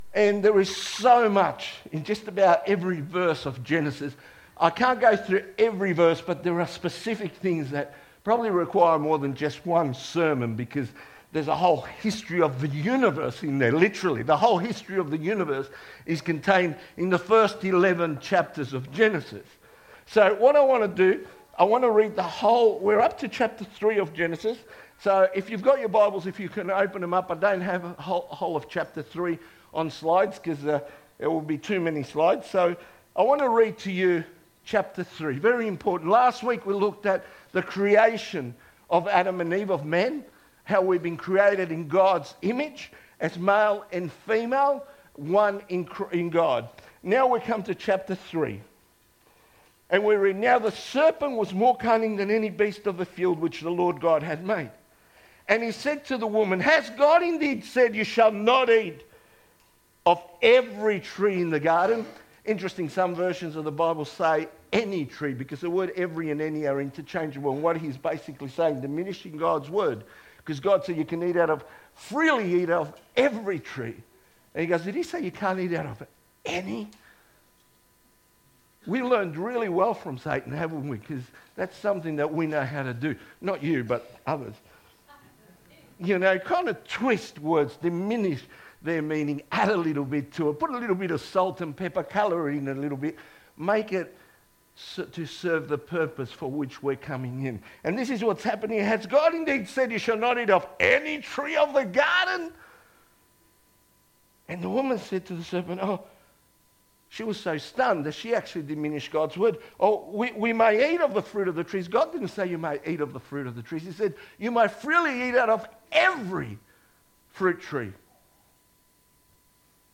2025 • 30.49 MB Listen to Sermon Download this Sermon Download this Sermon To download this sermon